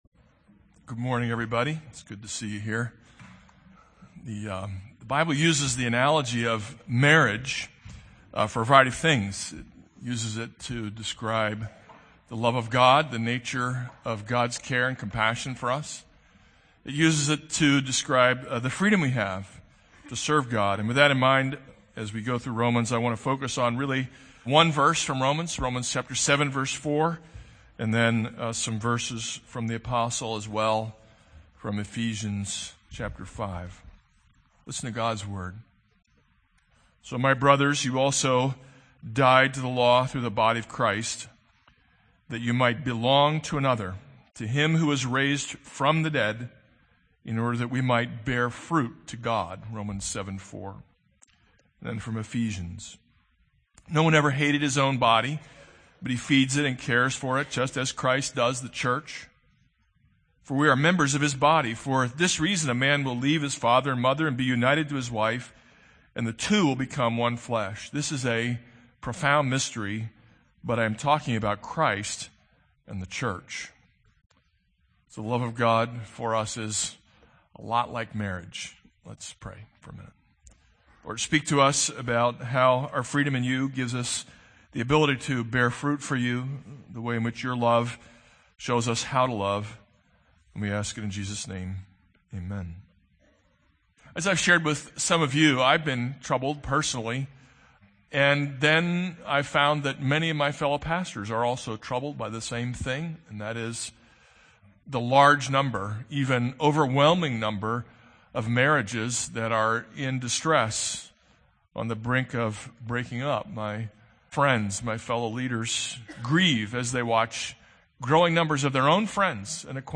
This is a sermon on Romans 7:1-4.